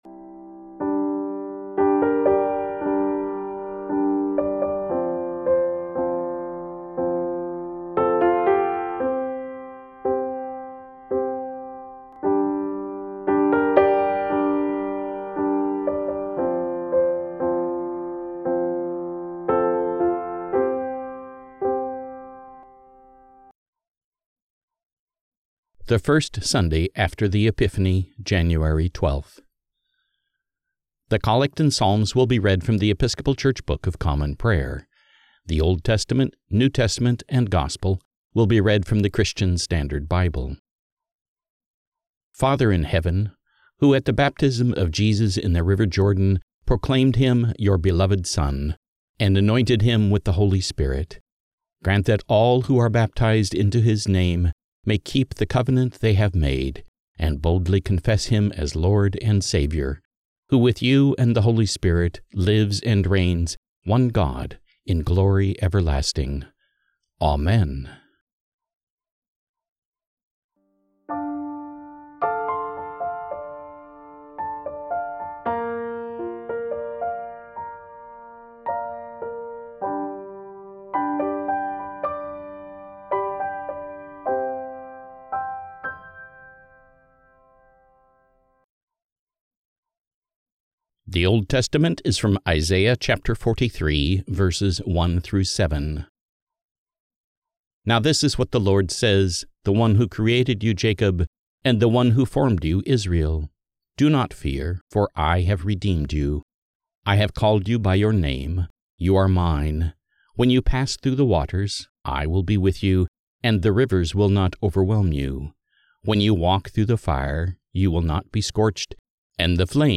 The Collect and psalms will be read from The Episcopal Church Book of Common Prayer
The Old Testament, New Testament and Gospel will be read from the Christian Standard Bible